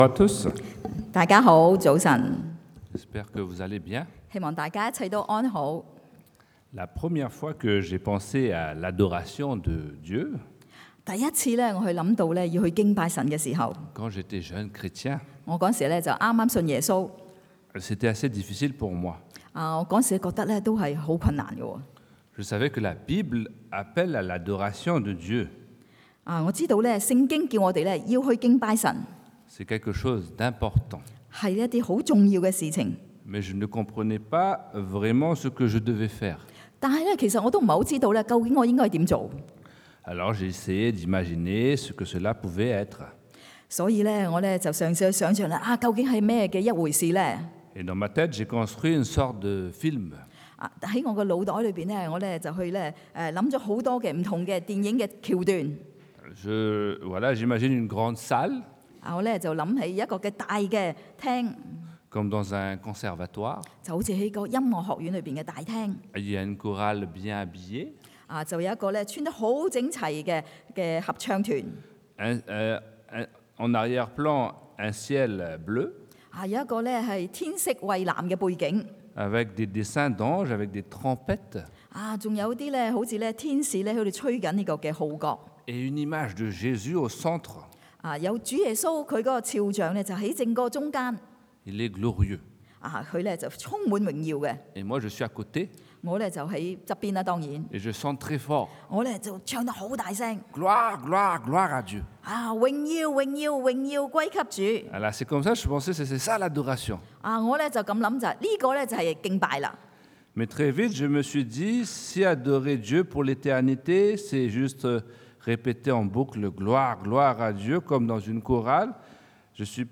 Redécouvrir l’adoration à Dieu 重新發現對上帝的敬拜 – Culte du dimanche